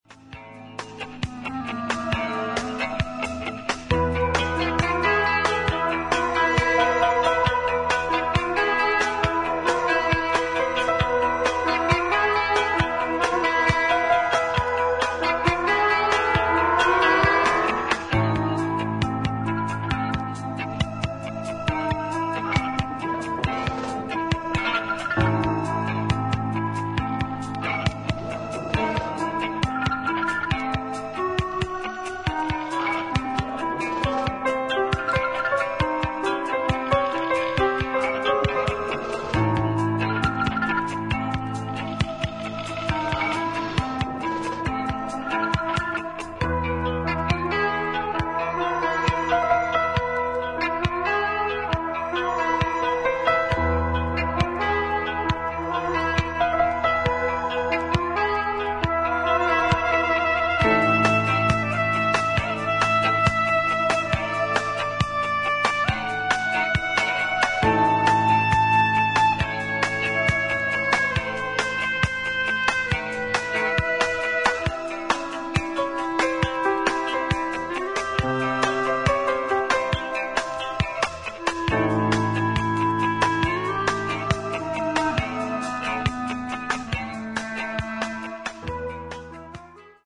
80'sエレクトロニックな感覚とシネマティックな雰囲気が随所に感じられるコンセプチュアルなコンピレーション作品